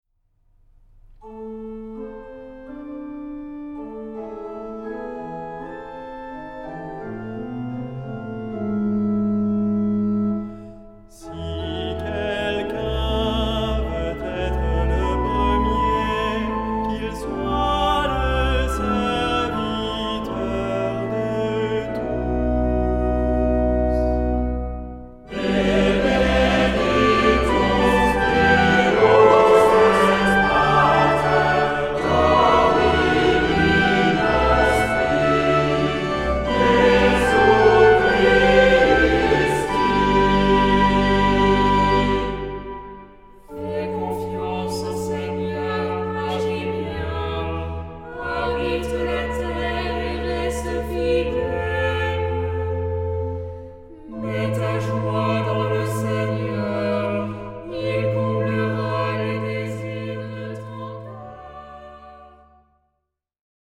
Genre-Style-Forme : Tropaire ; Psalmodie
Caractère de la pièce : recueilli
Type de choeur : SAH  (3 voix mixtes )
Instruments : Orgue (1) ; Instrument mélodique (1)
Tonalité : sol majeur ; mi mineur